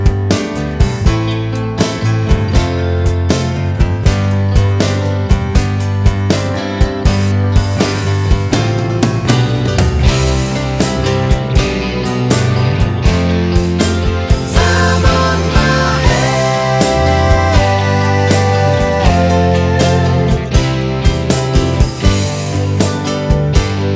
One Semitone Down Pop (1970s) 4:42 Buy £1.50